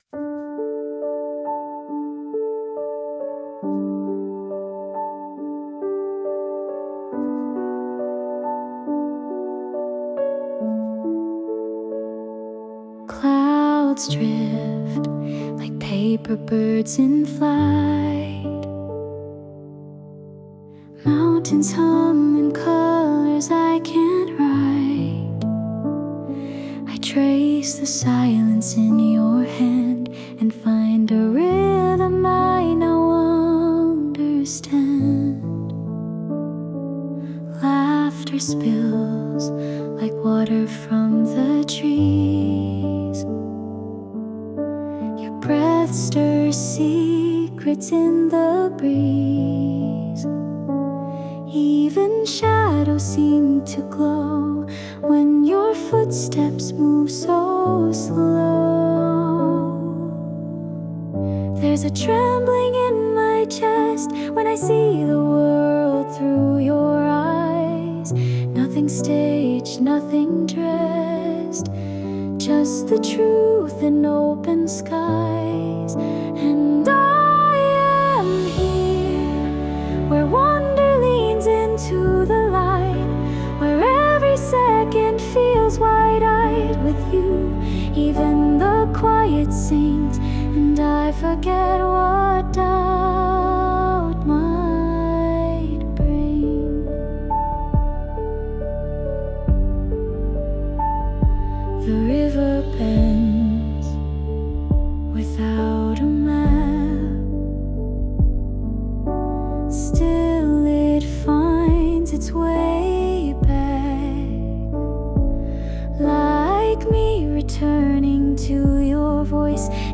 洋楽女性ボーカル著作権フリーBGM ボーカル
著作権フリーオリジナルBGMです。
女性ボーカル（洋楽・英語）曲です。